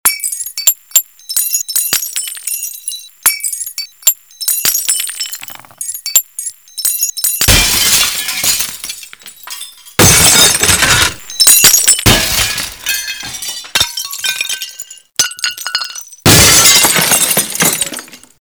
Звуки окна, стекла
Звук разбивающегося стекла